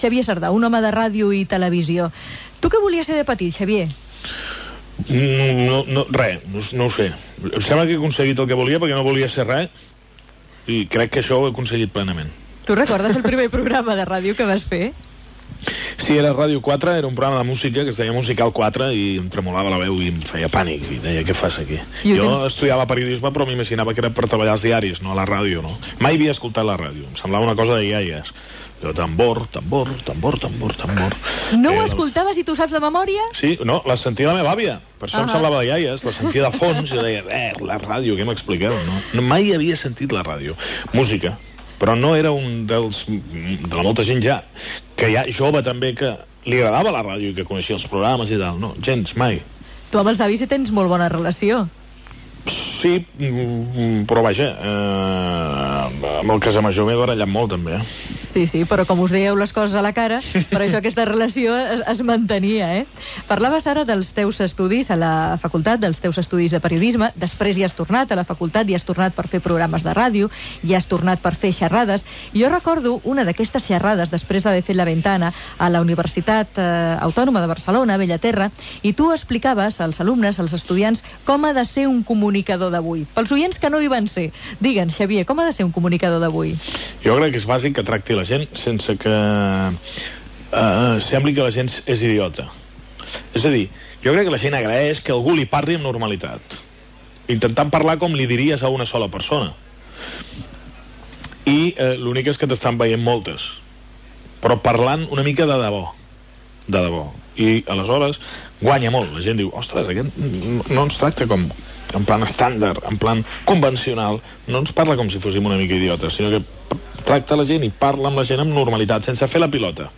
Entrevista a Xavier Sardà
Divulgació